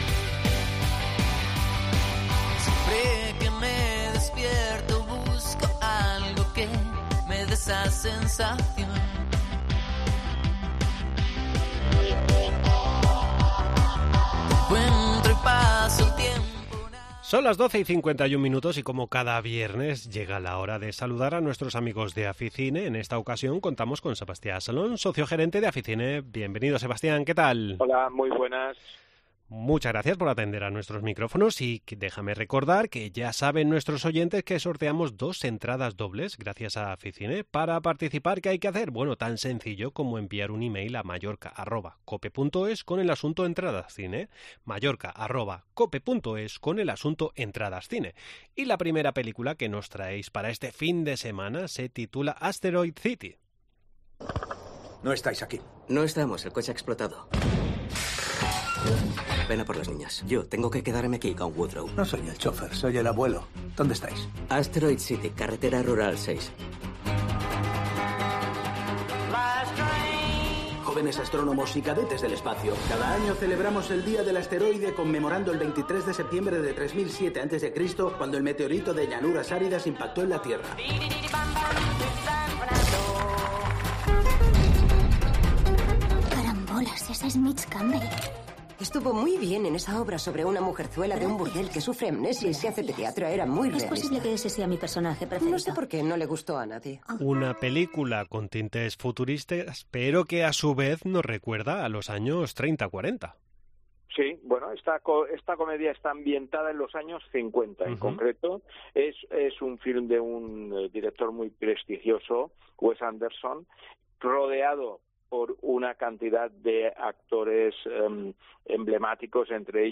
. Entrevista en La Mañana en COPE Más Mallorca, viernes 16 junio de 2023.